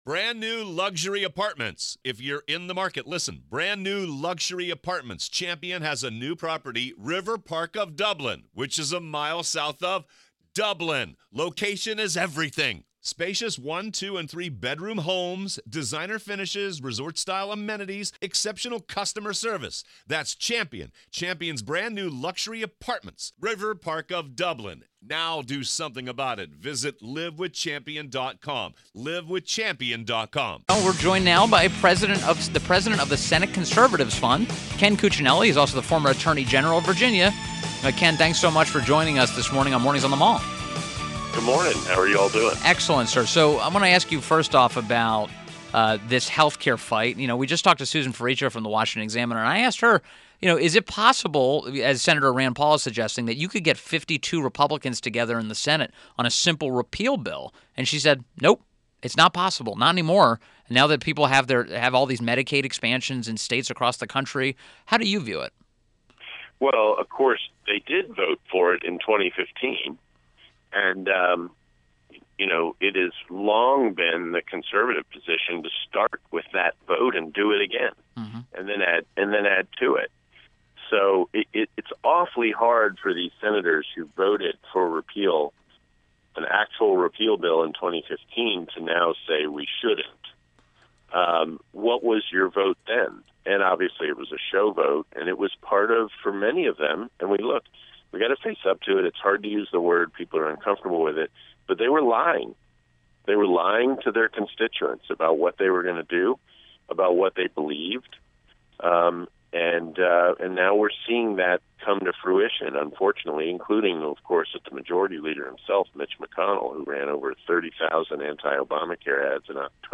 WMAL Interview - KEN CUCCINELLI 07.17.17